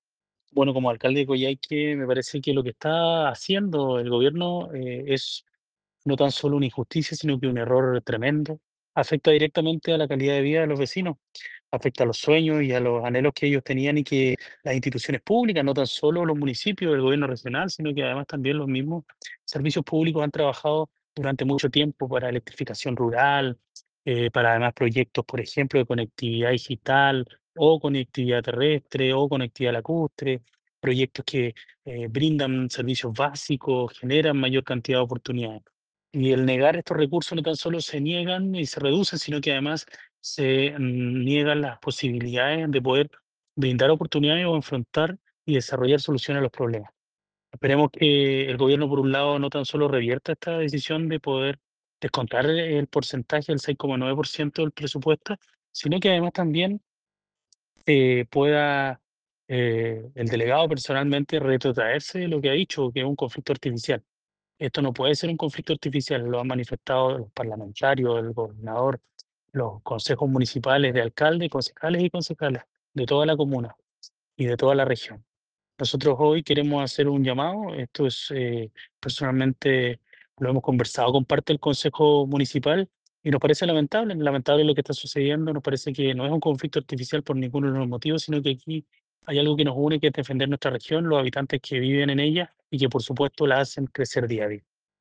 Alcalde de Coyhaique Carlos Gatica Villegas